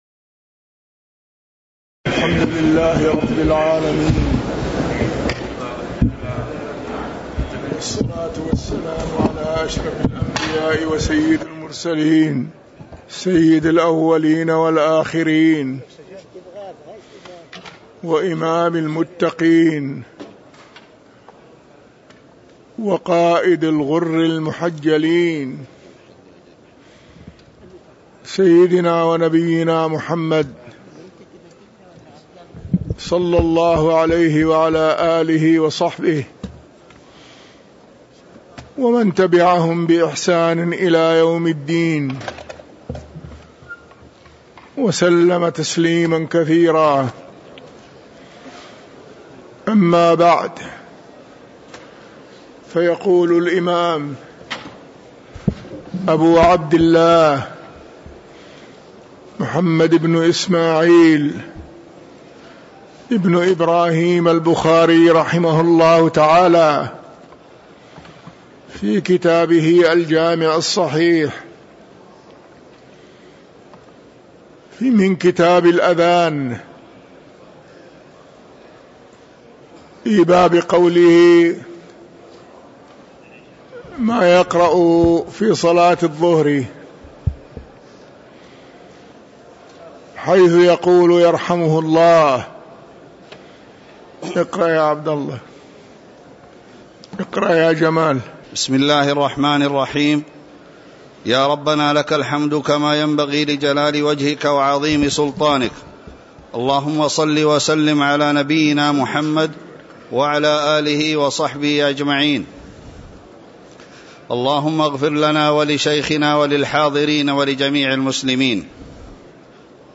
تاريخ النشر ١٩ صفر ١٤٤٣ هـ المكان: المسجد النبوي الشيخ